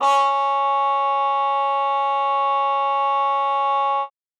Index of /90_sSampleCDs/Best Service ProSamples vol.42 - Session Instruments [AIFF, EXS24, HALion, WAV] 1CD/PS-42 AIFF Session Instruments/Trumpet cup mute